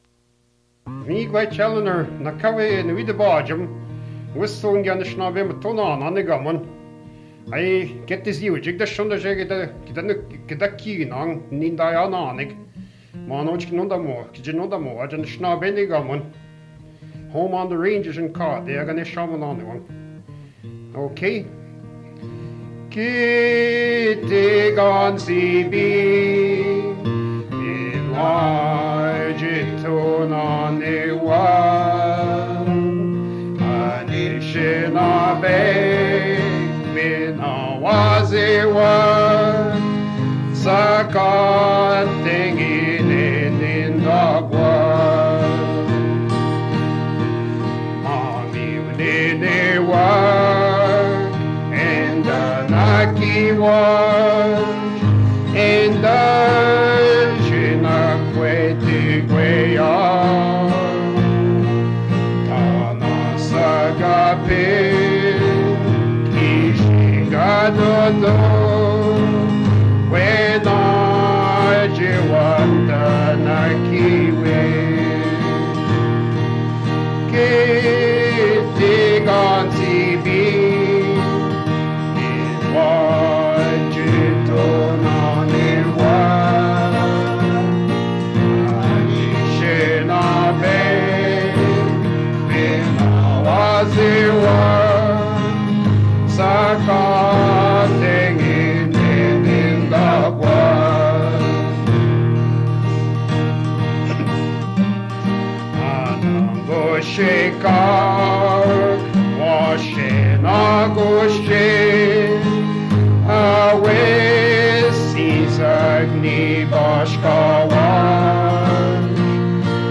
Fait partie de Country anicinabemowin music and a Country music